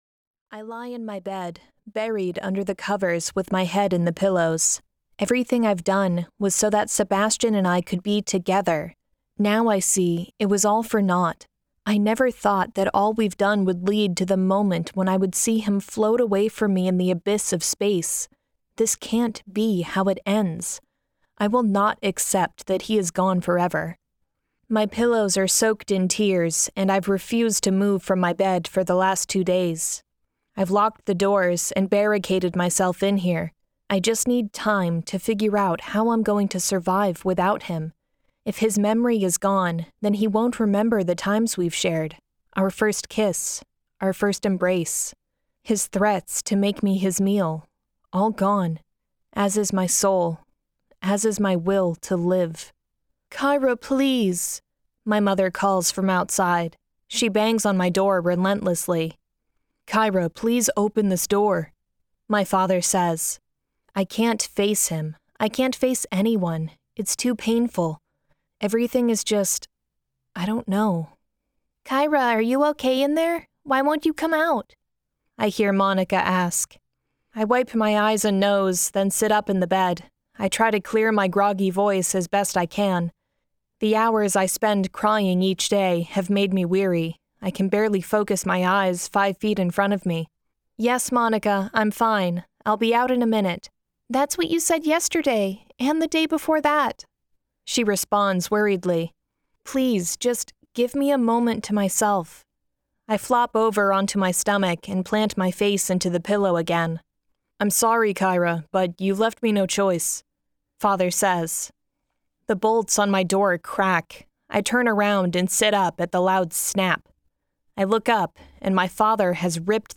The Revealing Audiobook | (The Blood's Passion Saga Book 5) 1st Edition